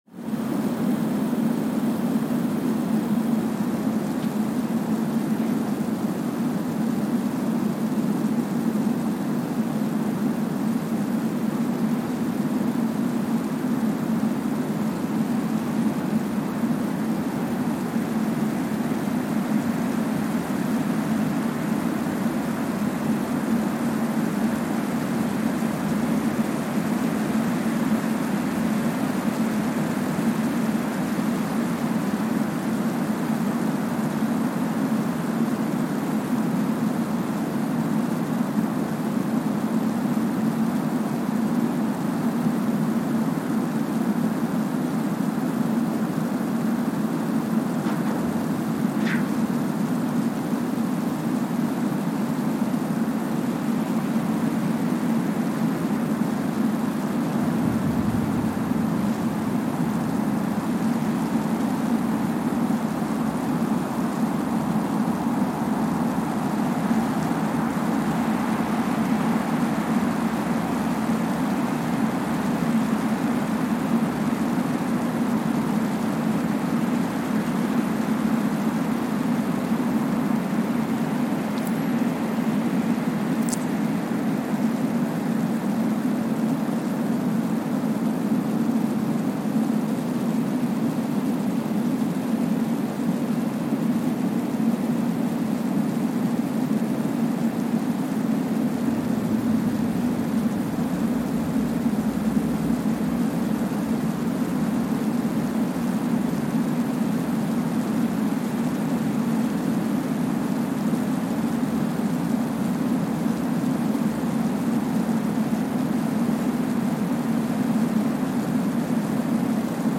Kwajalein Atoll, Marshall Islands (seismic) archived on May 27, 2023
Sensor : Streckeisen STS-5A Seismometer
Speedup : ×1,000 (transposed up about 10 octaves)
Loop duration (audio) : 05:45 (stereo)